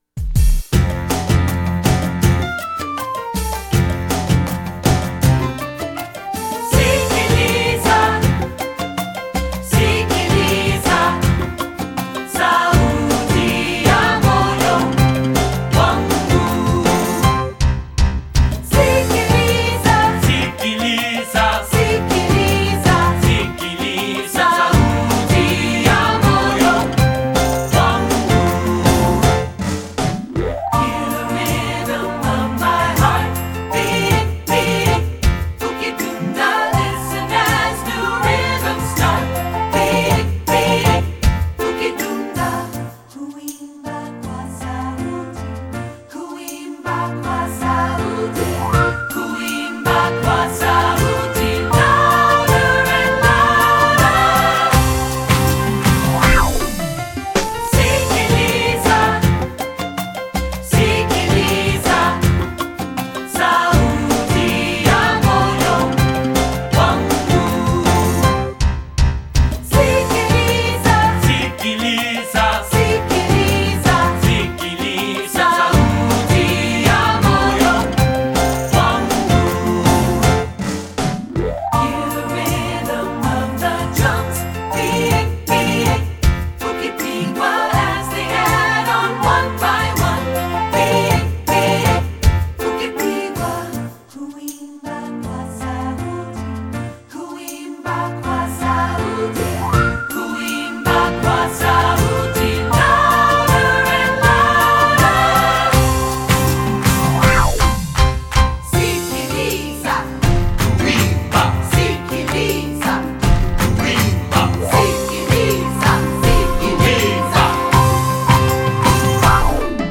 secular choral
2-part (3-part mixed recording)